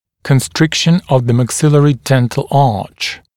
[kən’strɪkʃn əv ðə mæk’sɪlərɪ ‘dentl ɑːʧ][кэн’стрикшн ов зэ мэк’силэри ‘дэнтл а:ч]сужение зубного ряда верхней челюсти